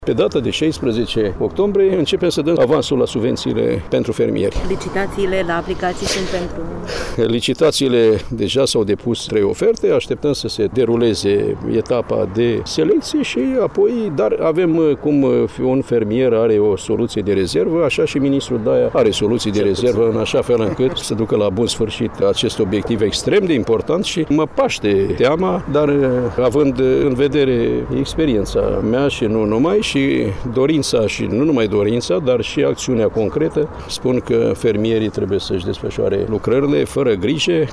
Primii care vor primi, în avans, o parte a subvenţiilor, sunt fermierii din sectorul vegetal, urmând ca fermierii din domeniul zootehnic să primească avansul plăţilor naţionale începând din luna decembrie. Anunţul a fost făcut, ieri, în comuna Leţcani, judeţul Iaşi, de către ministrul agriculturii, Petre Daea.